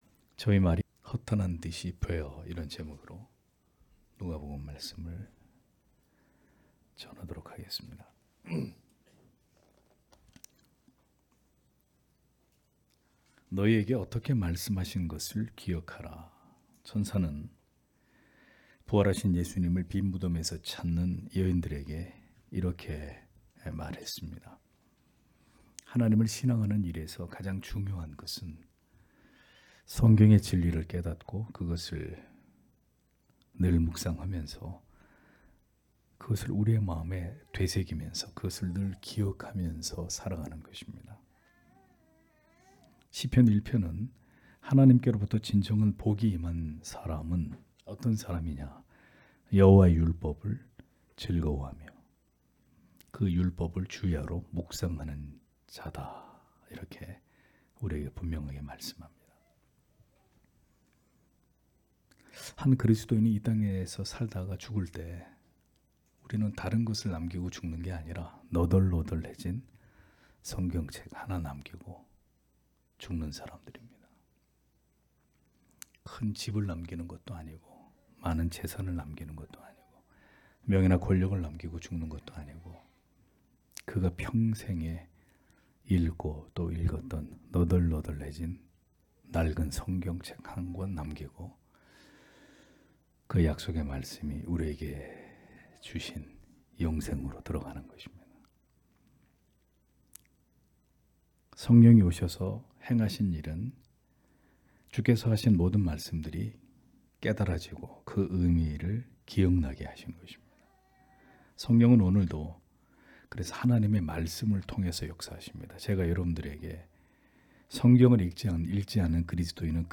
금요기도회 - [누가복음 강해 183] '저희 말이 허탄한 듯이 뵈어' (눅 24장 11- 12절)